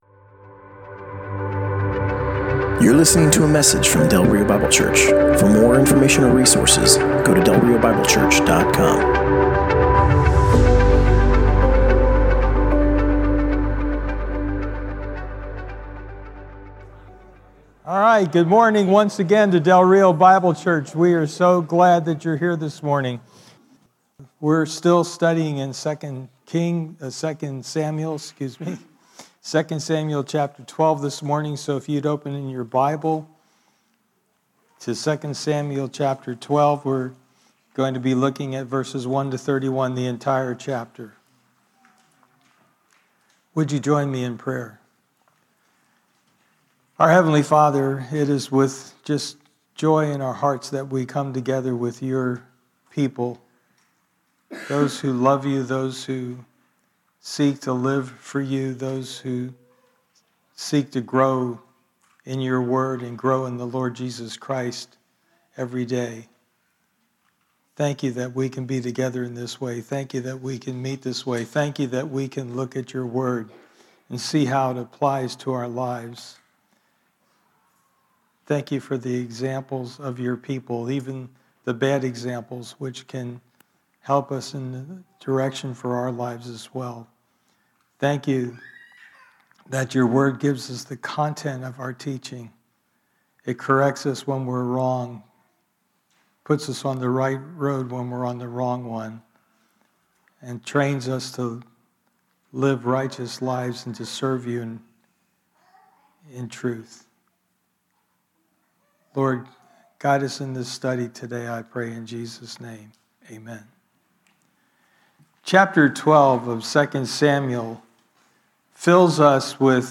Passage: 2 Samuel 12: 1-31 Service Type: Sunday Morning